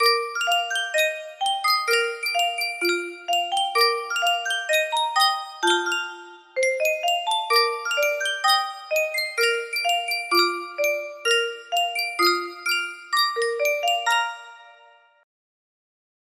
Yunsheng Music Box - Nobody Knows the Trouble I've Seen 6506 music box melody
Full range 60